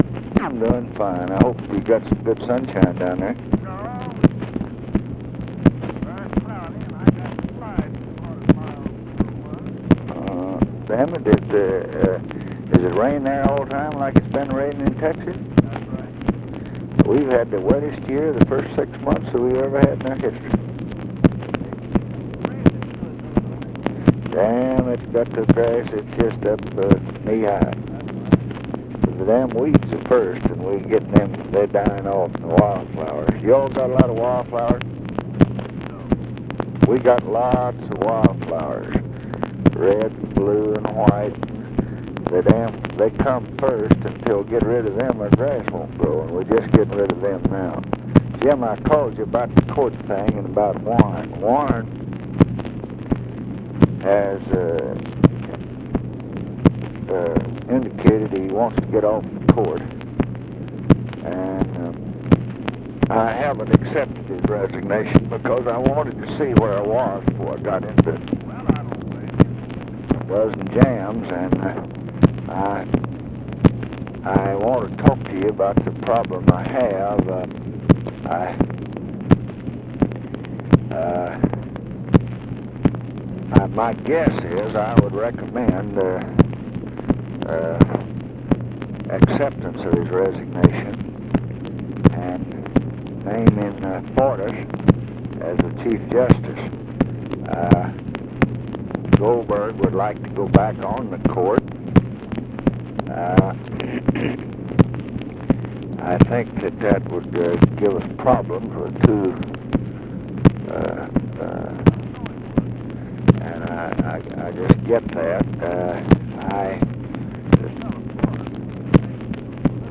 Conversation with JAMES EASTLAND, June 23, 1968
Secret White House Tapes